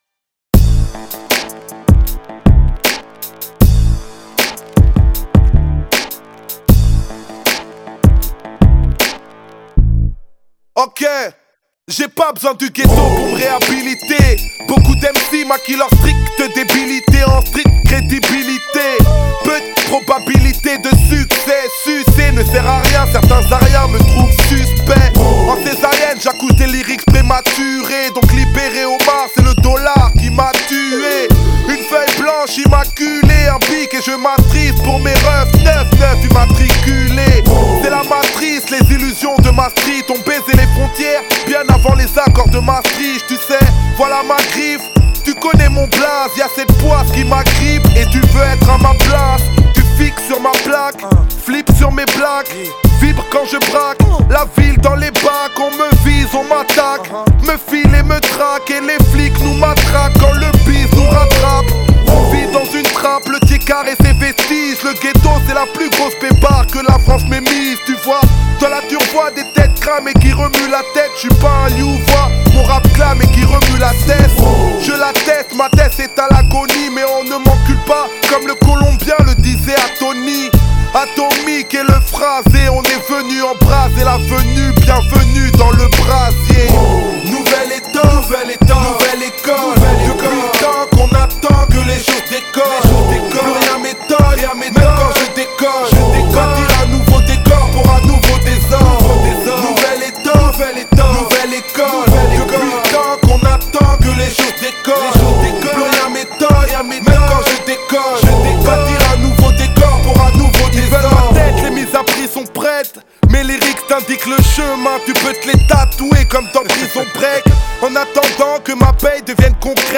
Genre: French Rap